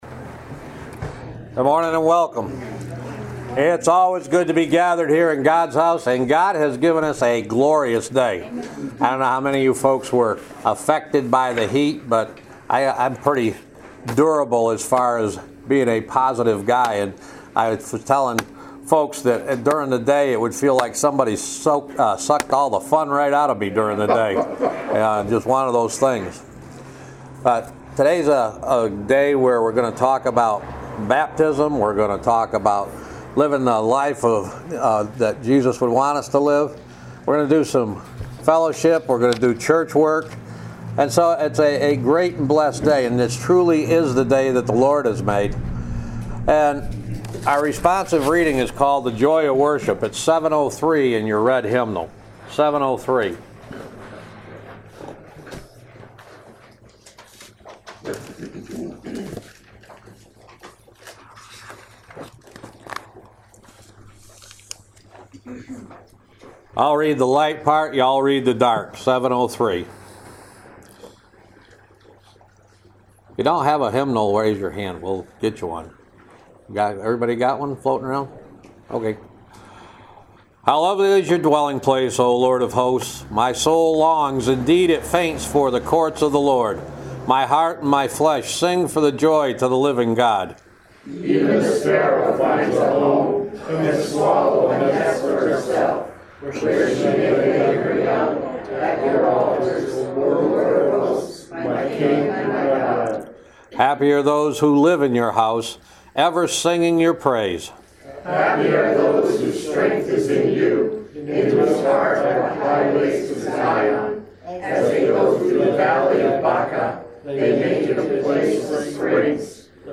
August 7th, 2016 Service + Communion Podcast
Welcome to the August 7th, 2016 Service + Communion Podcast.